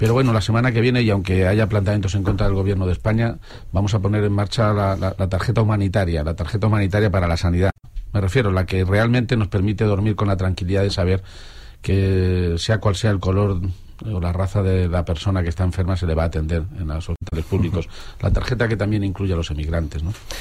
Presidente Jueves, 4 Febrero 2016 - 11:45am El presidente castellano-manchego, Emiliano García-Page, ha anunciado hoy en una entrevista a RCM que la semana que viene van a poner en marcha la “Tarjeta Humanitaria” de Sanidad, con la que cualquier persona que esté enferma, sea del color o la raza que sea, va a ser atendida en los hospitales públicos. La tarjeta permite atender también a los emigrantes, ha añadido. audio_garcia-page_tarjeta_humanitaria.mp3 Descargar: Descargar